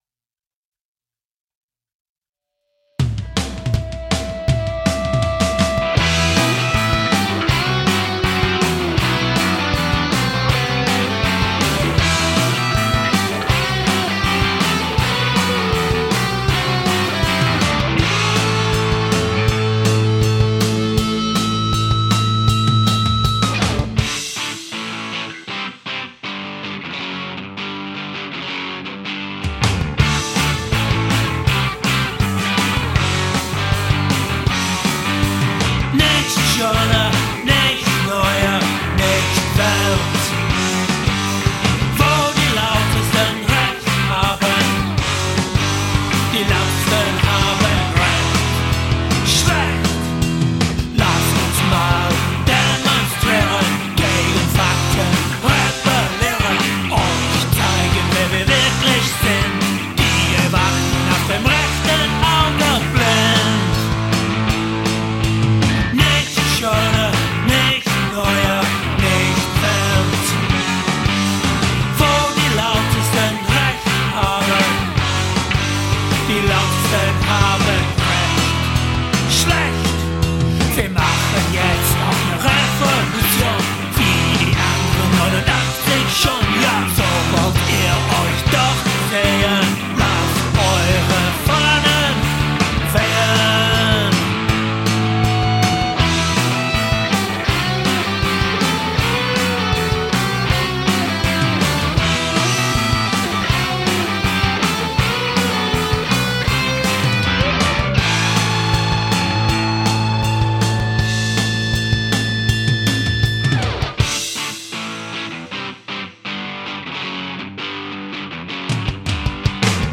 I am also singing in German.
The German language really suits old school Punk, don't you think?